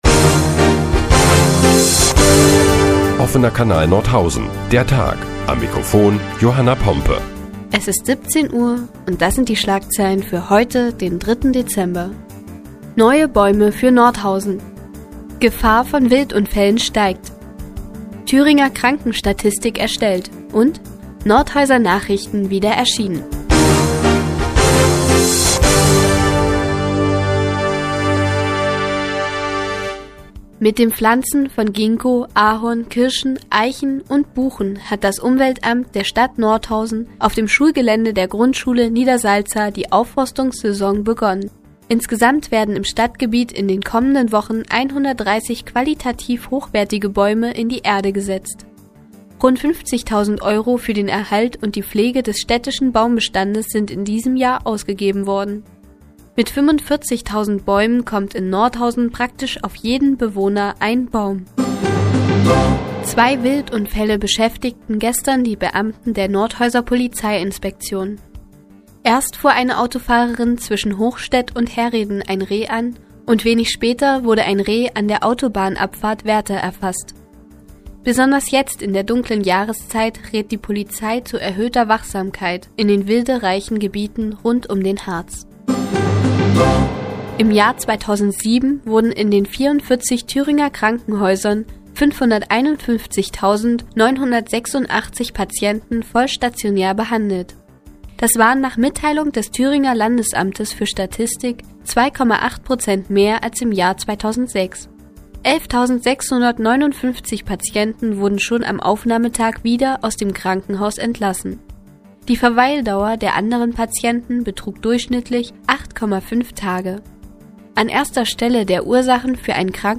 Die tägliche Nachrichtensendung des OKN ist nun auch in der nnz zu hören. Heute geht es unter anderem um neue Bäume für Nordhausen und die Thüringer Krankenstatistik.